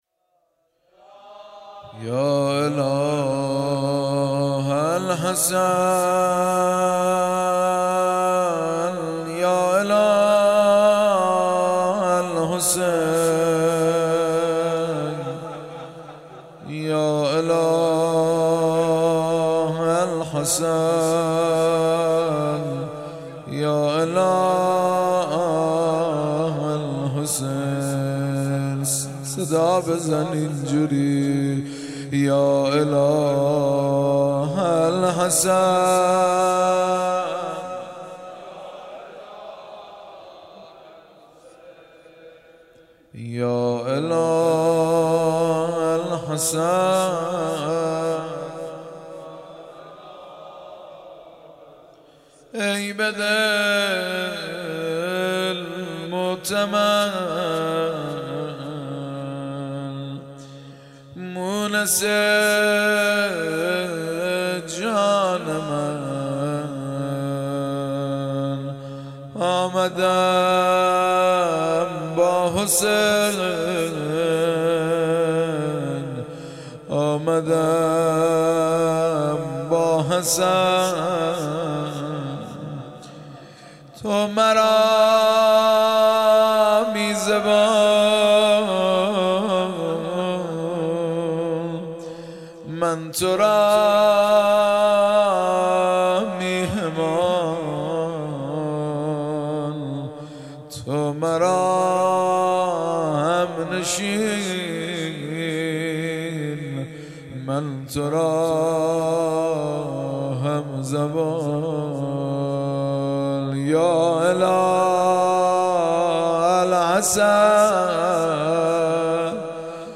مراسم شب شانزدهم ماه رمضان
مداحی
درامامزاده اسماعیل(ع) چیذر برگزار گردید.